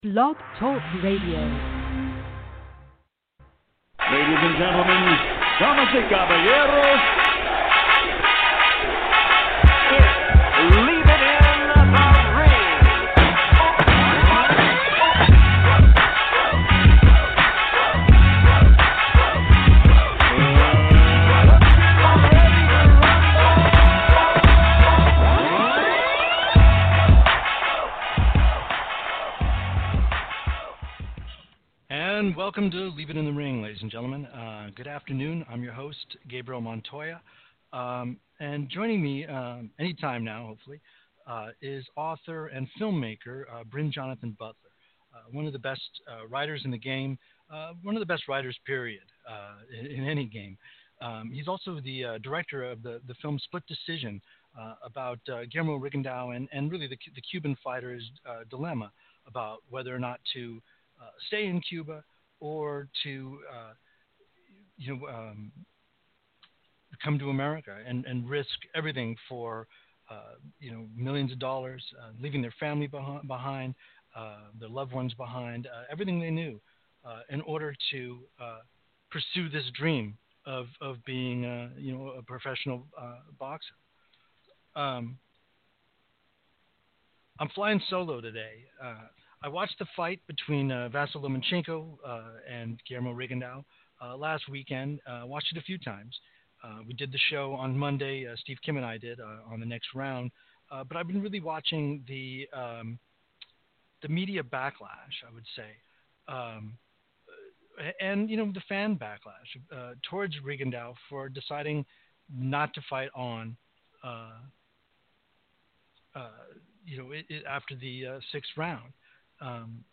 exclusive one-on-one interview